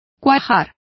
Complete with pronunciation of the translation of maw.